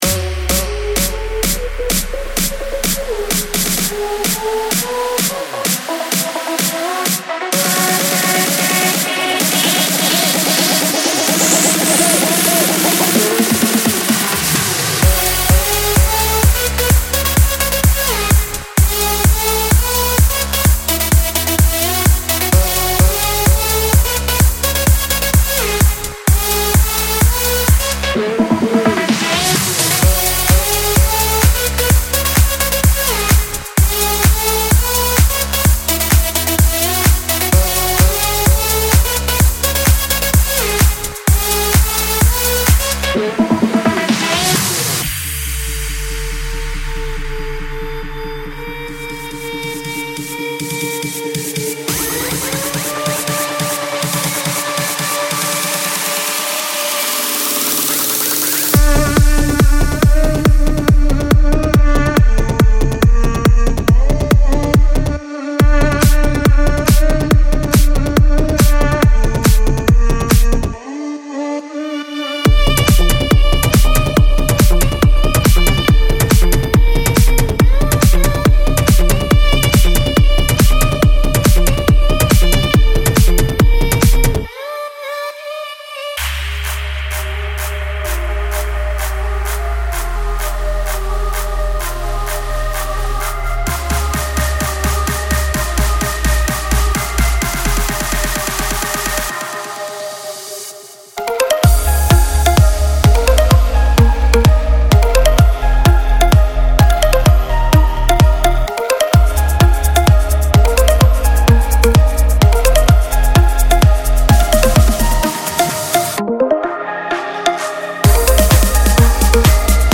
庞大的旋律，杀手级的低音，敲打的鼓声和族裔的声音片段构成了这个令人惊叹的系列的支柱。
•声乐片段，低音，合成器，FX等